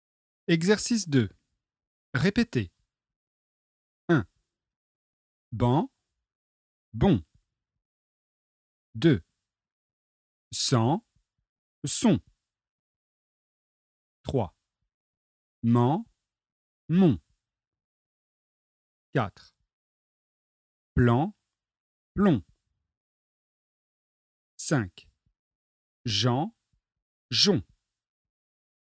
★　Exercice 2 : répétez ( paires minimales AN/ON)